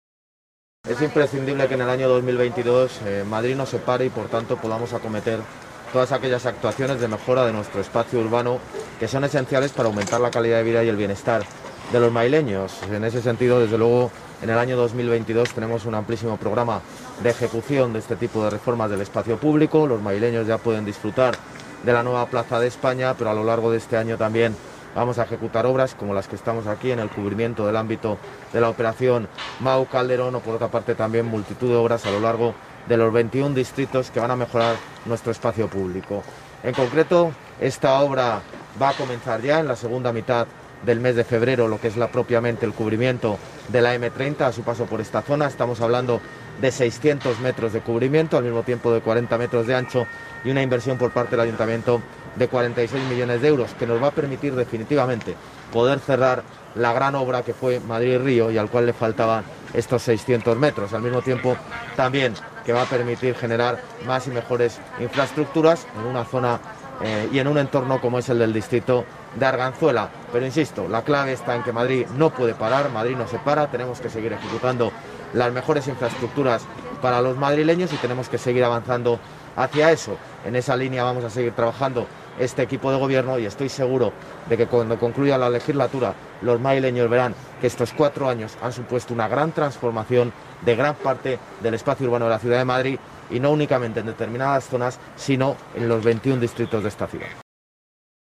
El alcalde de Madrid ha visitado los trabajos acompañado por la delegada de Obras y Equipamientos y la concejala de Arganzuela
Nueva ventana:José Luis Martínez-Almeida, alcalde de Madrid